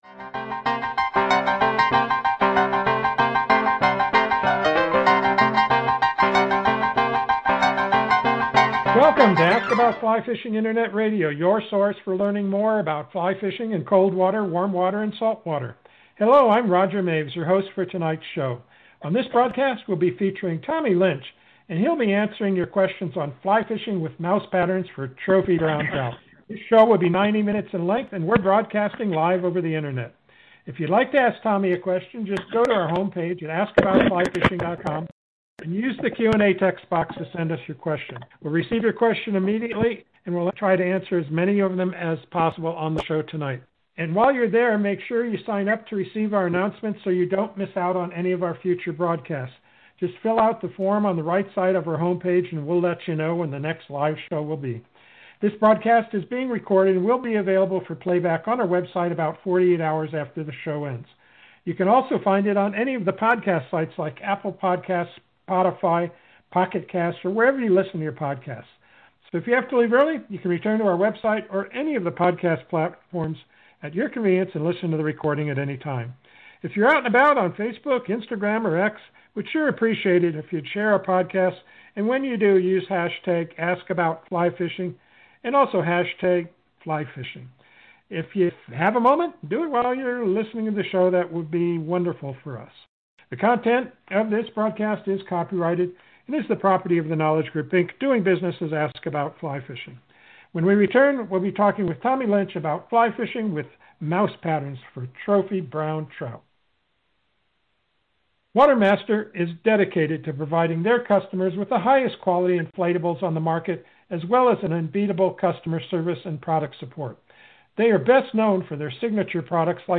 Just a few of the questions asked and answered during the interview: